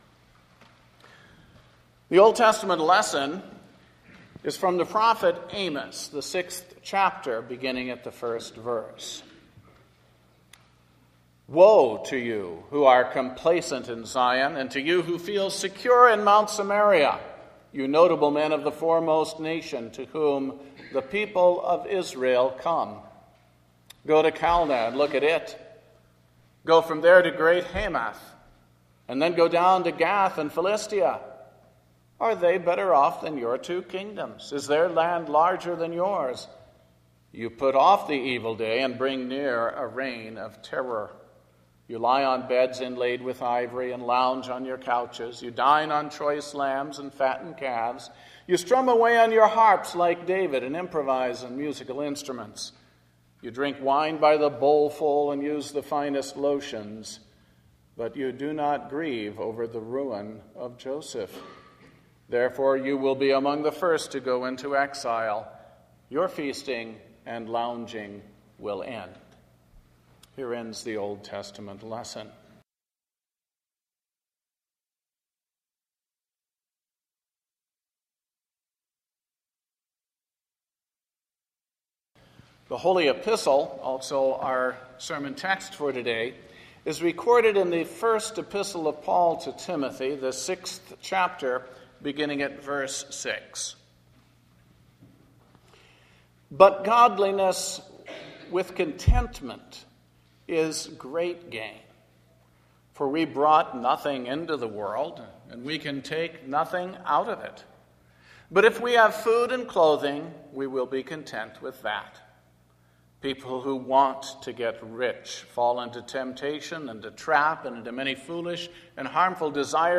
Culture – Page 2 – St. Timothy Evangelical Lutheran Church in Lombard Illinois